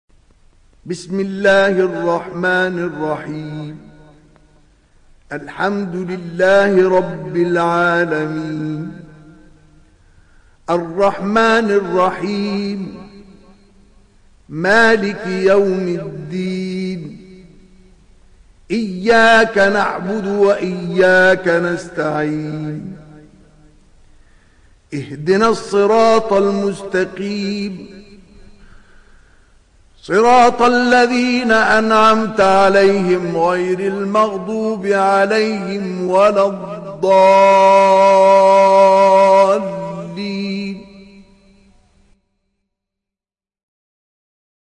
Sourate Al Fatiha Télécharger mp3 Mustafa Ismail Riwayat Hafs an Assim, Téléchargez le Coran et écoutez les liens directs complets mp3